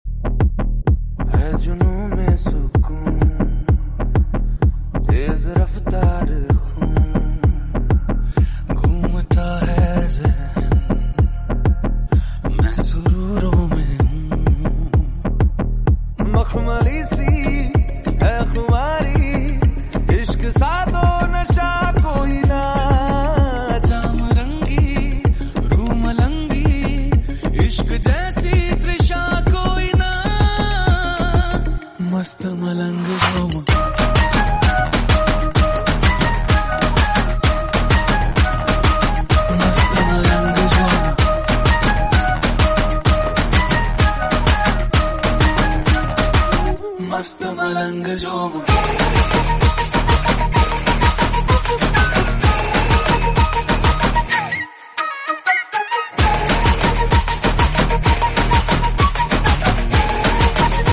AWM Head Shot 😱AWM Head Sound Effects Free Download